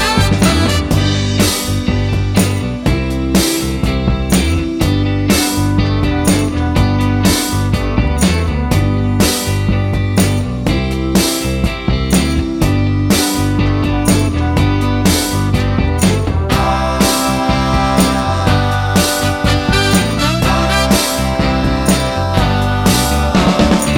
no Backing Vocals Soul / Motown 2:49 Buy £1.50